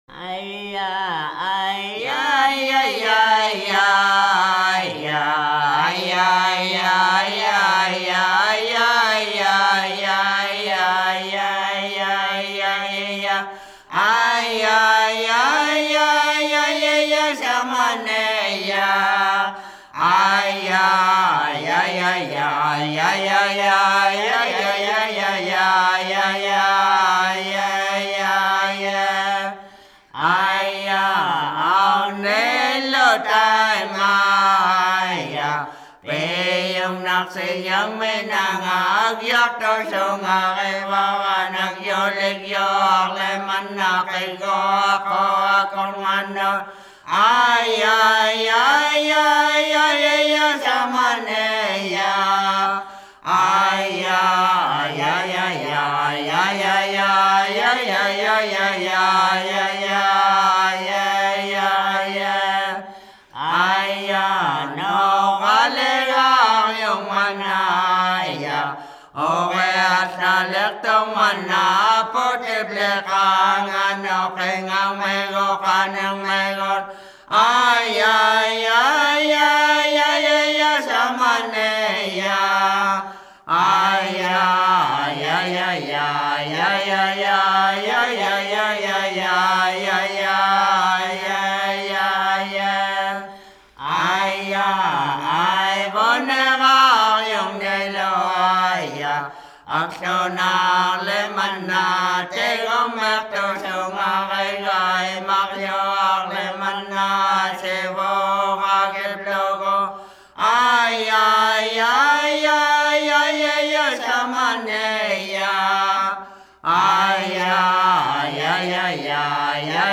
Шаманские песнопения в технике северного горлового пения.
Никаких обработок, никаких сэмплов - чистая аутентика!